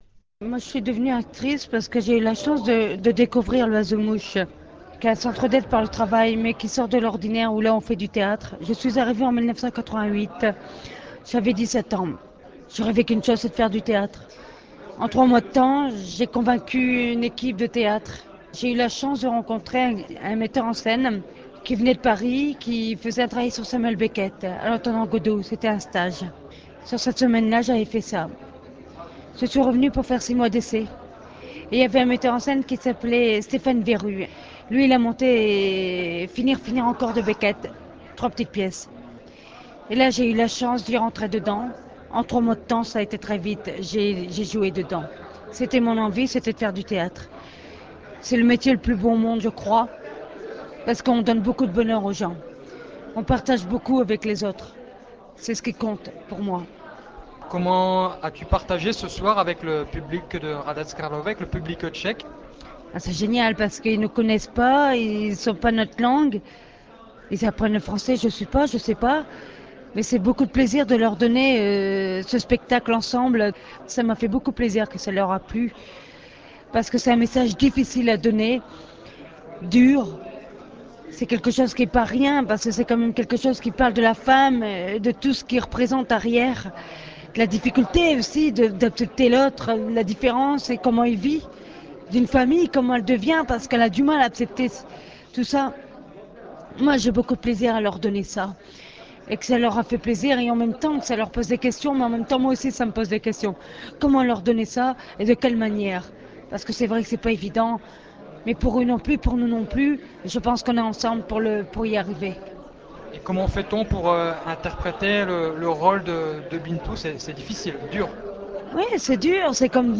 J'espère que le choix des extraits de quelques interviews intéressantes que mes collègues ont réalisées au cours de cette année fera plaisir à vous tous qui êtes actuellement à l'écoute.
D'abord comédien très populaire, puis diplomate, il vit aujourd'hui un grand come-back de nouveau comme comédien. Quand il raconte, on ne se lasse pas de l'écouter, d'autant qu'il est parfaitement francophone.